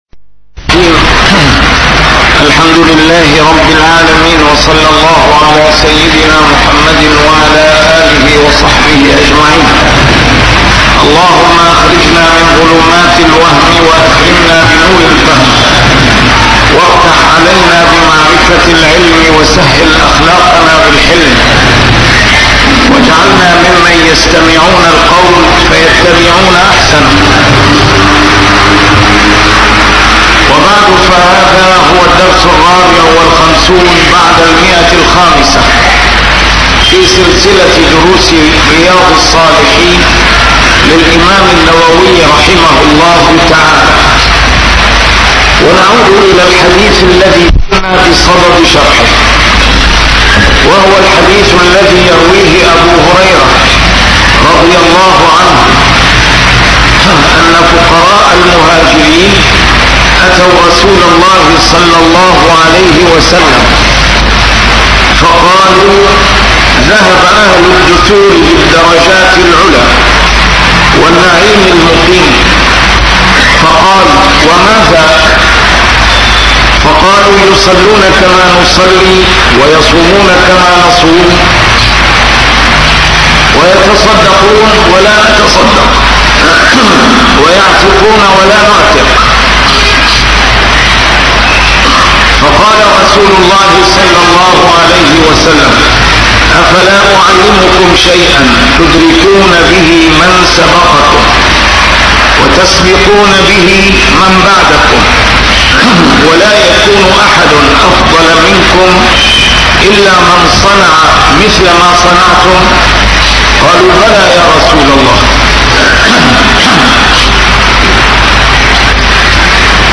A MARTYR SCHOLAR: IMAM MUHAMMAD SAEED RAMADAN AL-BOUTI - الدروس العلمية - شرح كتاب رياض الصالحين - 554- شرح رياض الصالحين: الغني الشاكر